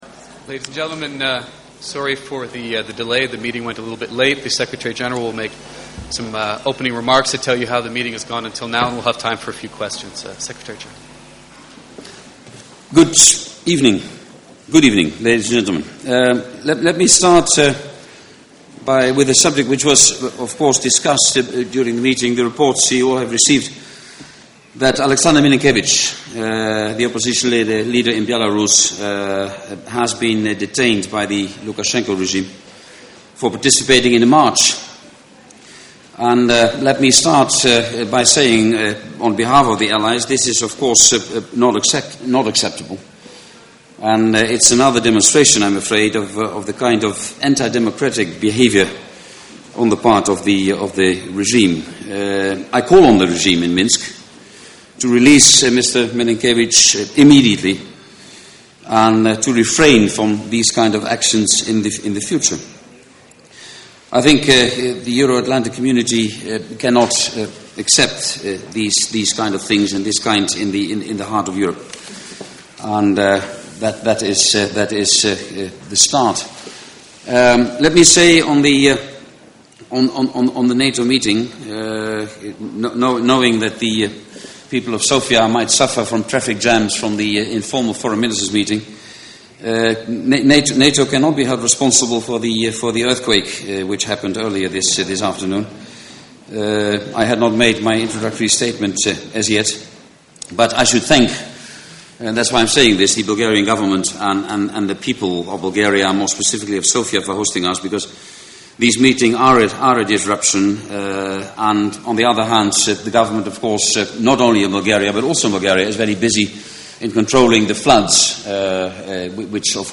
Audio Press conference by NATO Secretary General Jaap de Hoop Scheffer following the informal meeting of the North Atlantic Council at the level of Foreign Ministers in Sofia, Bulgaria, opens new wind